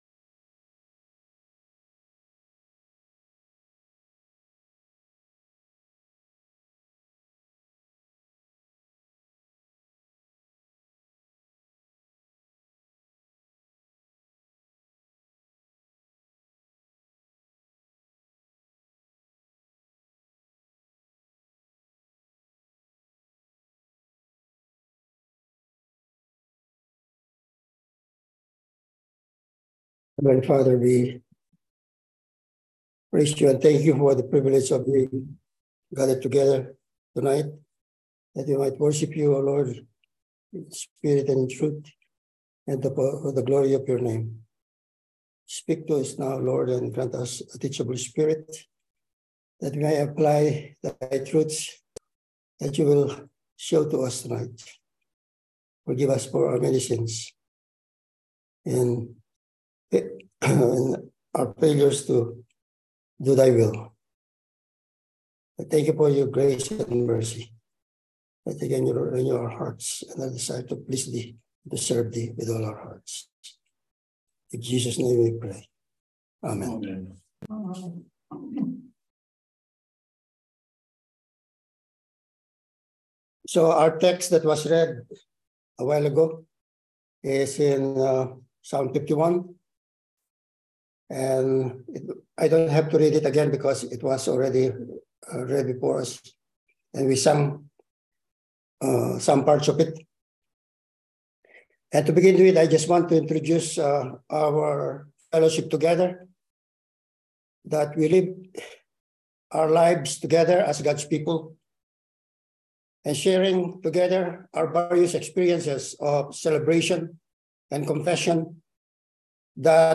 Passage: Psalm 51 Service Type: Sunday Evening Service True Repentance « Sunday Morning